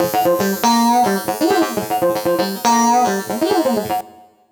120FUNKY19.wav